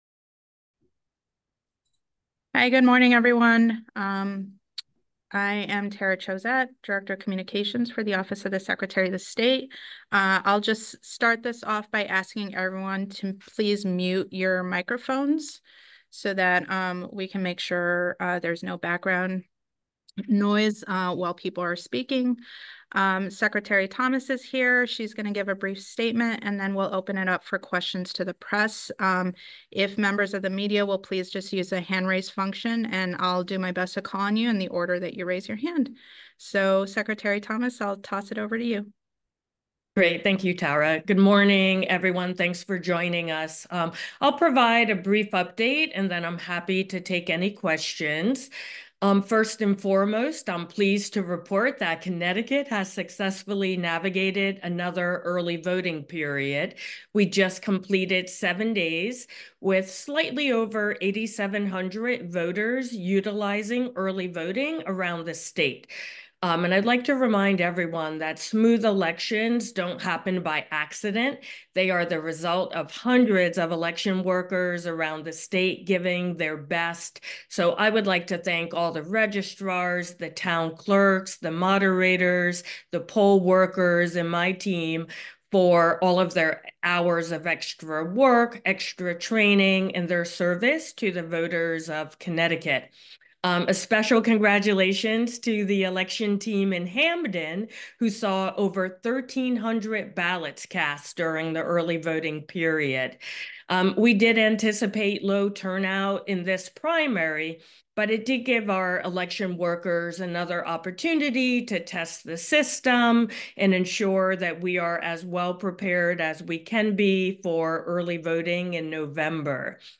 (Hartford, Connecticut) – In a press conference earlier today, Secretary of the State Stephanie Thomas highlighted the smooth operations of the start of the August 13th Primary and its concluded early voting period, and she also thanked election workers for their efforts over the seven-day early voting period.
sots-press-conference---2024-august-primary.mp3